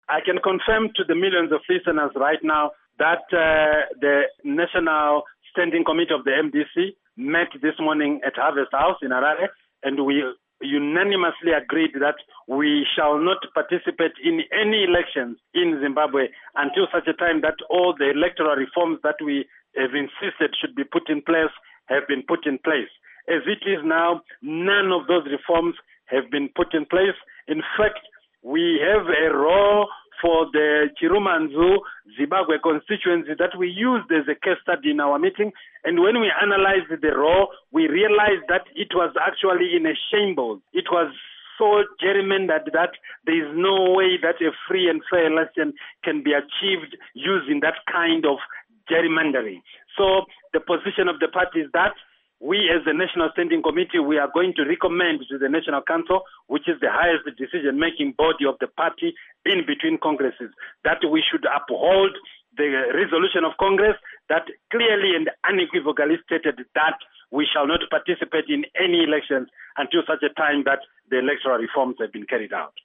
Interview With Obert Gutu